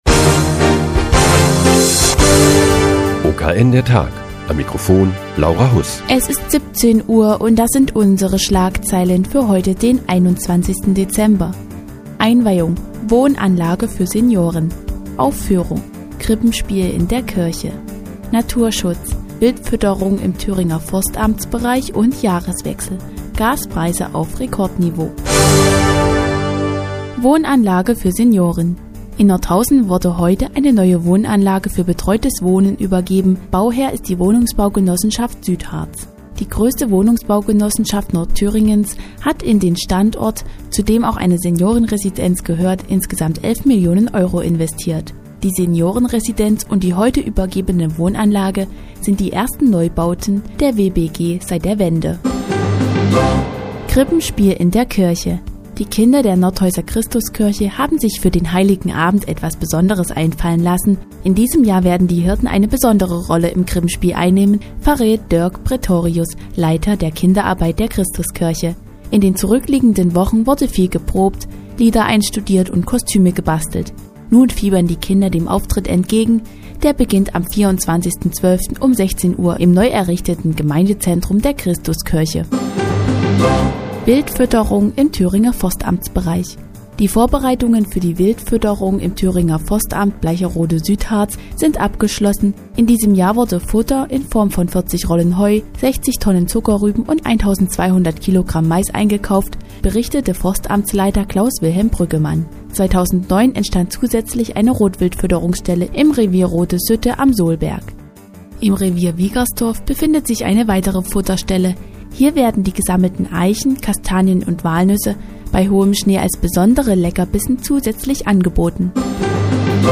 Die tägliche Nachrichtensendung des OKN ist nun auch in der nnz zu hören. Heute geht es um die neue Nordhäuser Wohnanlage für Senioren und das Krippenspiel zum Heiligen Abend in der Christuskirche.